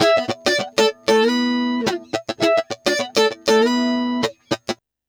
100FUNKY09-R.wav